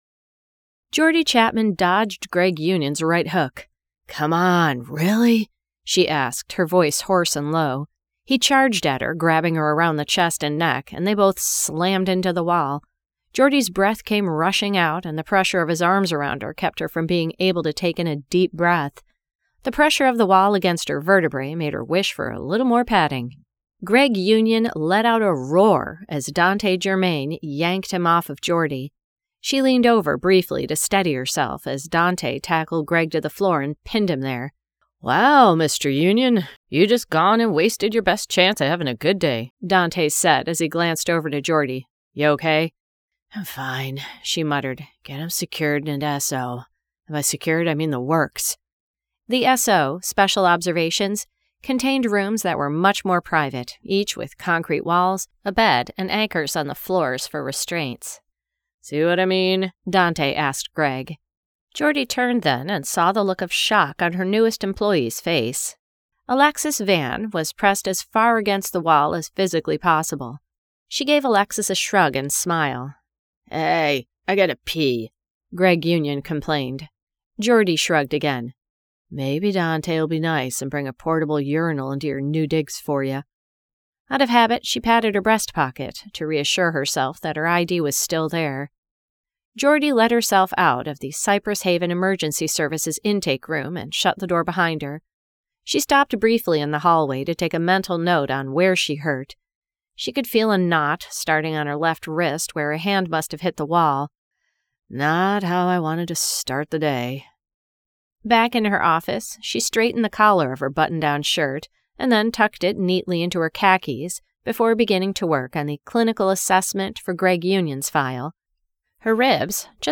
Pausing by Renee MacKenzie [Audiobook]